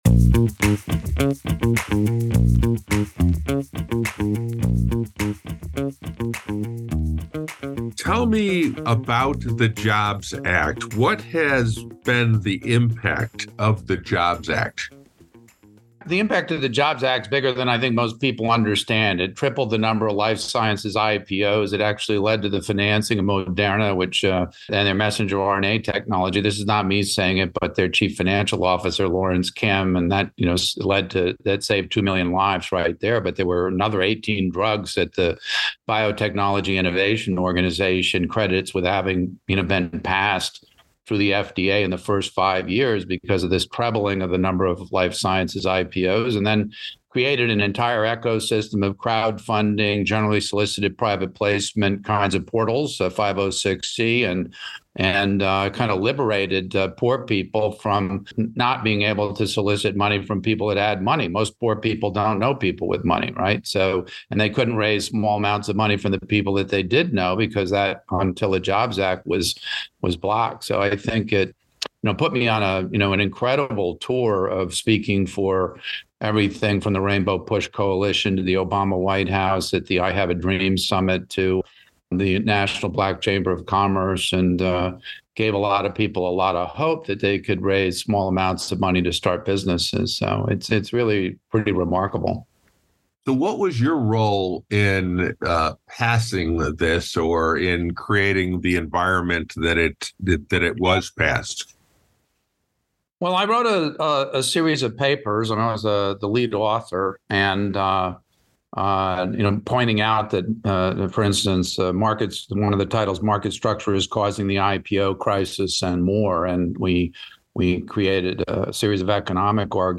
John Lothian News Interviews